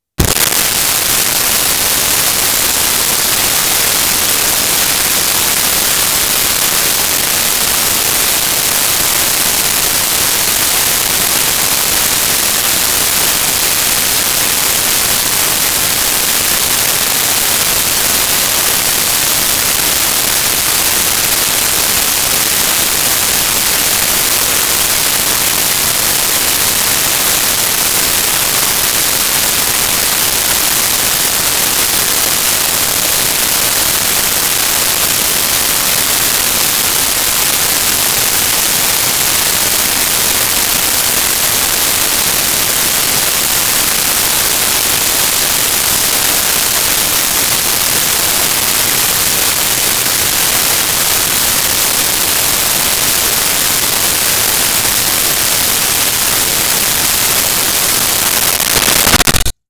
Kitchen Sink Washing
Kitchen Sink Washing.wav